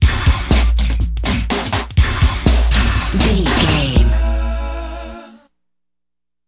Ionian/Major
D
synthesiser
drum machine
electric guitar
drums
strings
90s